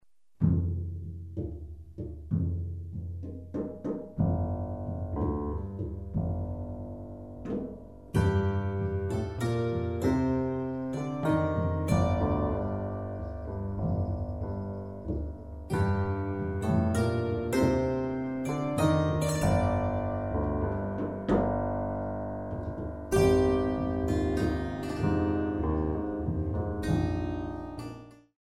3/4  mm=98